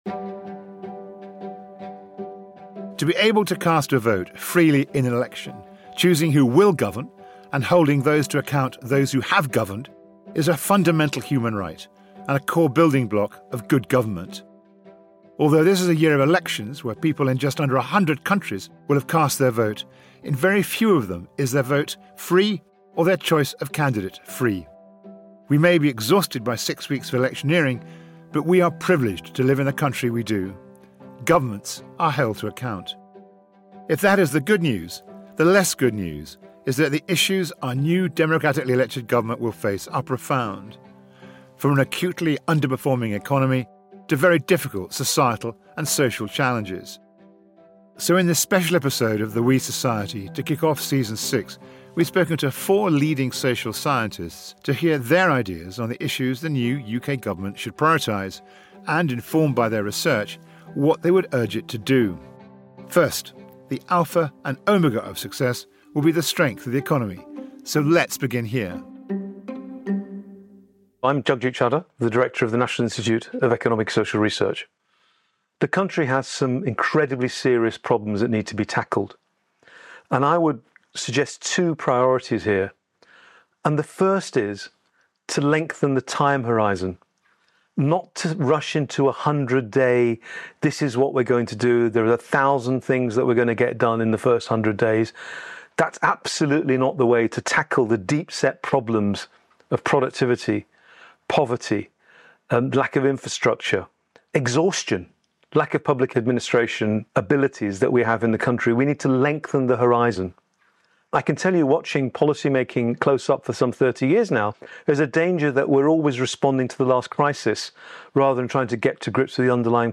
Host Will Hutton hears from four leading social scientists on how the new UK government should tackle the country's most pressing challenges.